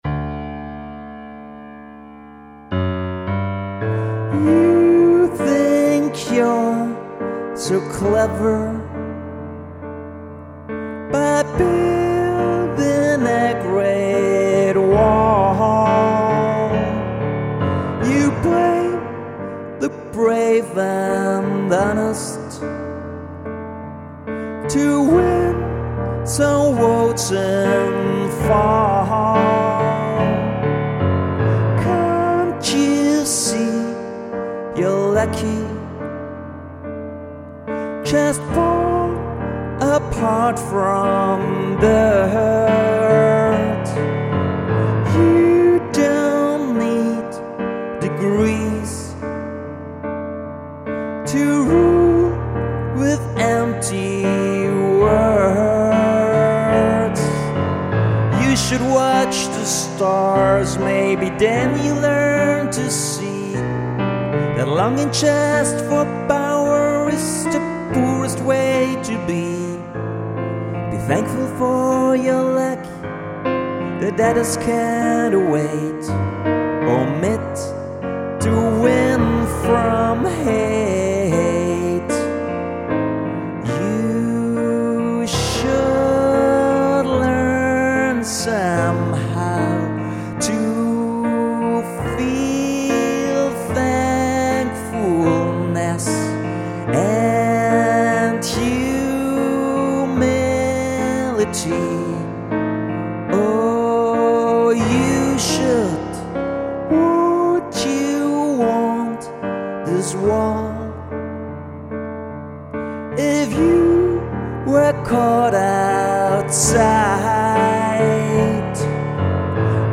vocals, piano